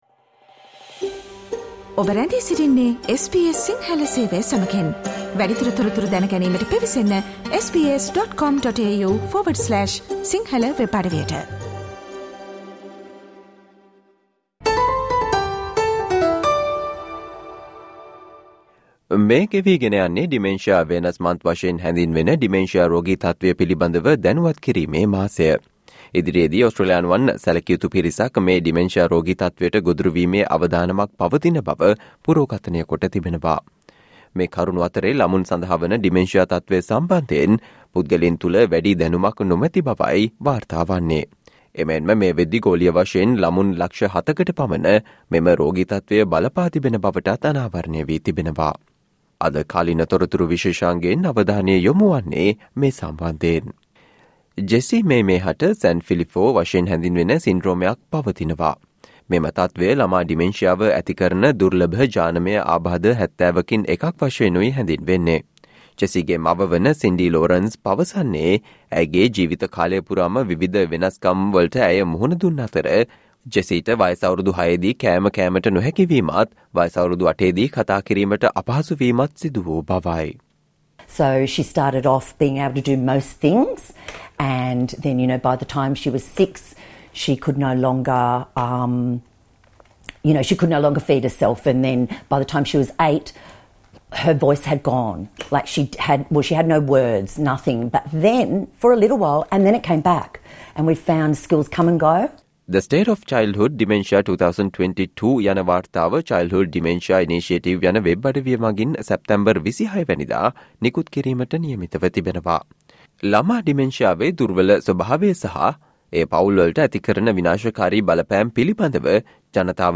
Today - 23 September, SBS Sinhala Radio current Affair Feature on Australians with childhood dementia need more support: advocates